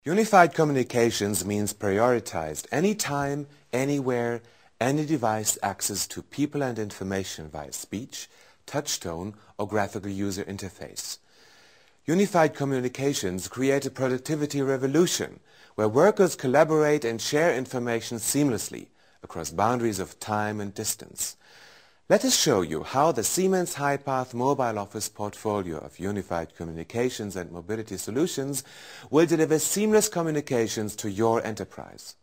Sprechprobe: Industrie (Muttersprache):
german voice over artist.